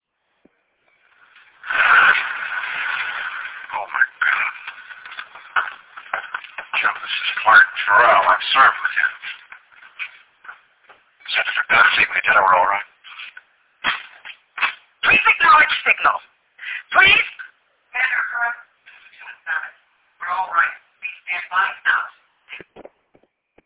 Quality is low, but the rarity of these materials makes them an absolute treasure.